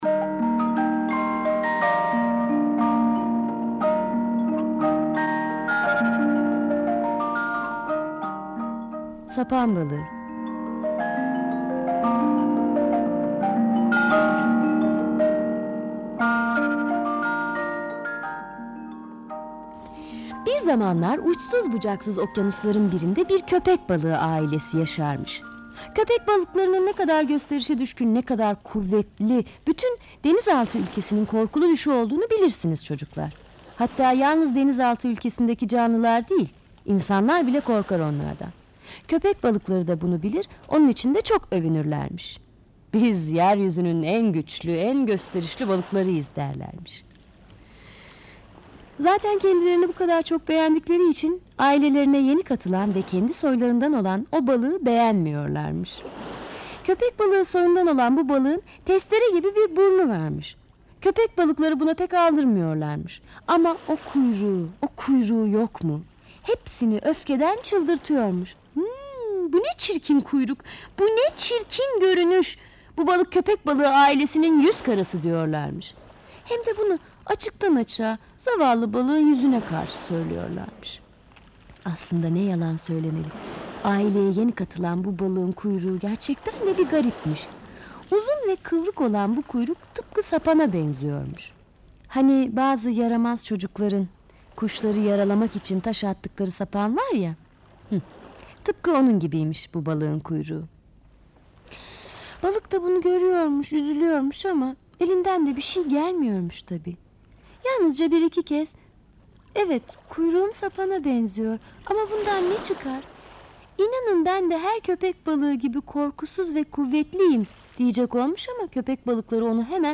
Sapan balığı sesli masalı, mp3 dinle indir
Sesli Çocuk Masalları